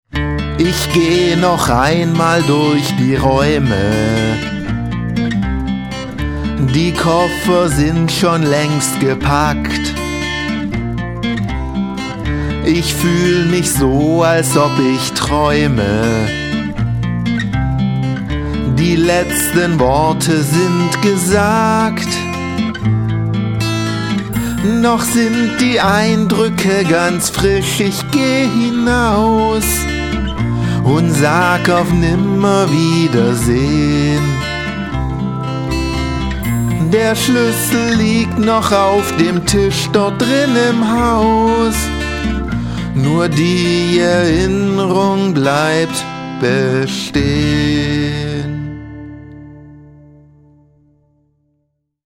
Zur Abwechslung mal ein Lied, bei dem ich nicht hektisch jede Menge Silben in die Takte presse.